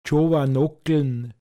pinzgauer mundart